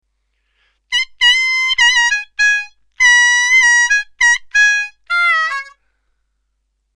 diatonic harmonica